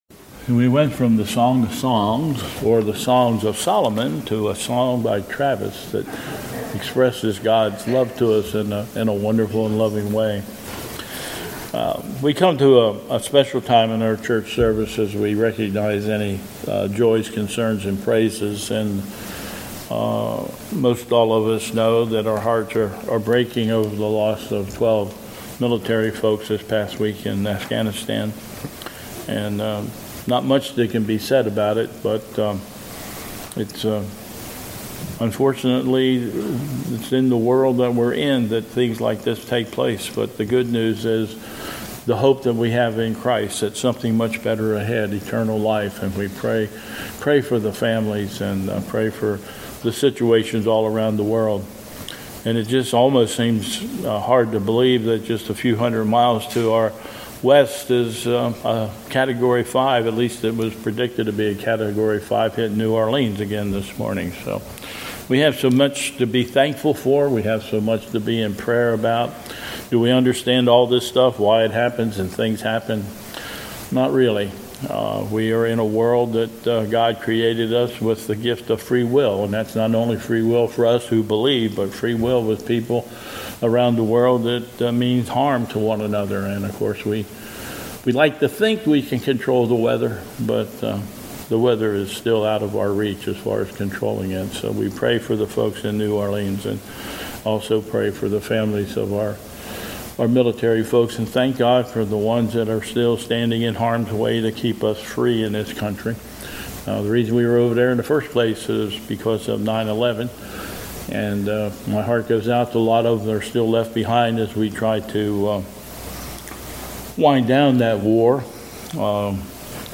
2021 Bethel Covid Time Service
Prayer Concerns
Closing with Communal prayer and the Lord's Prayer